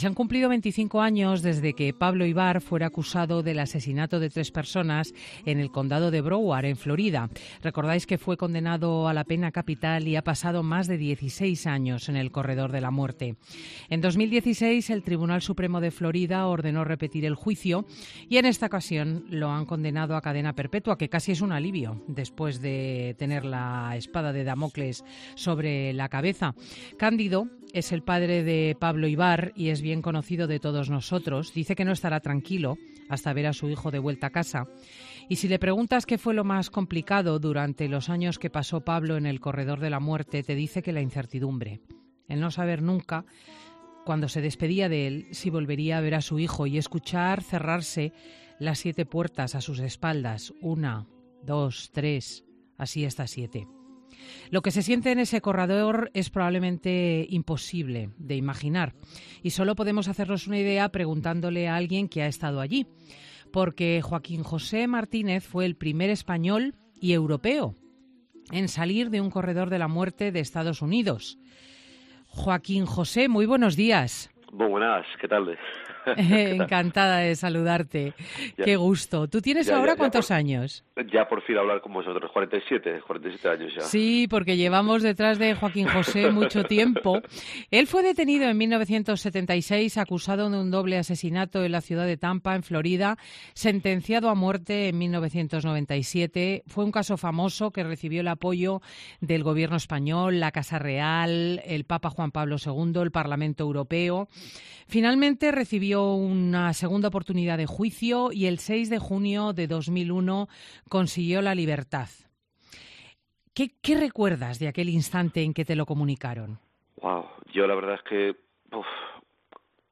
entrevistas en profundidad